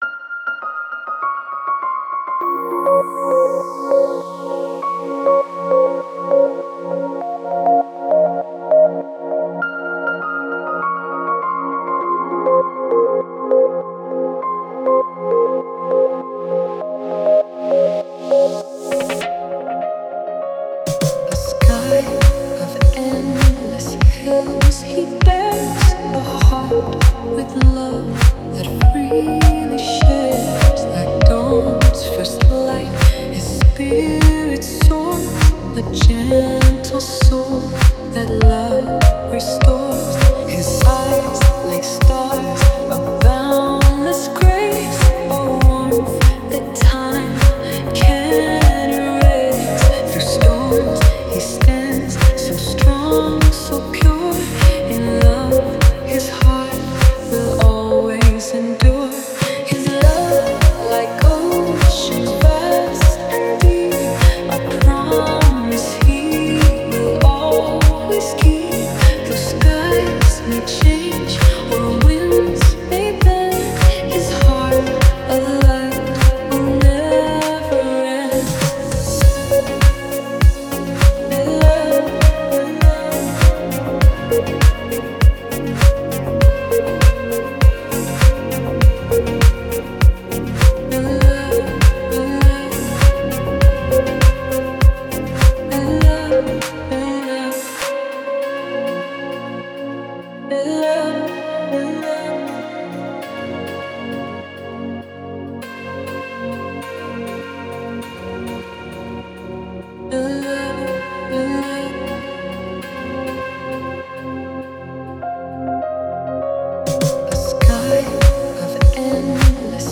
спокойные песни , душевная музыка , спокойная музыка